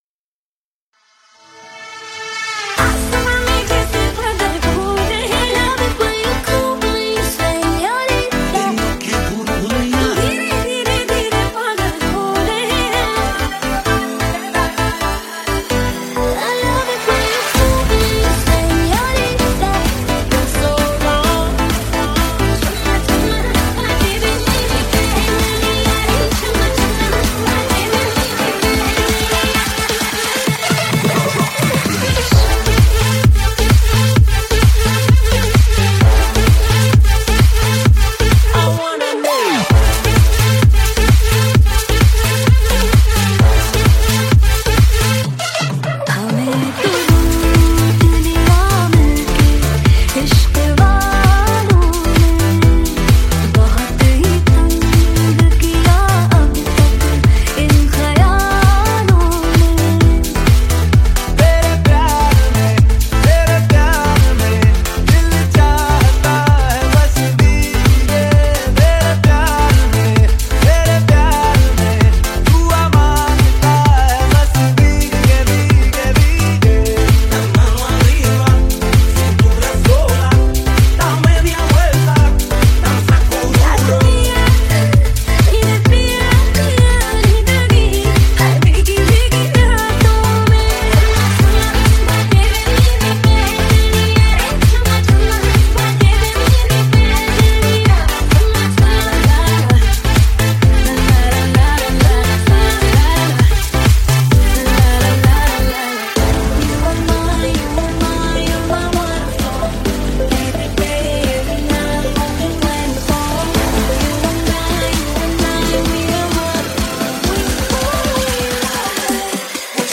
Dj Song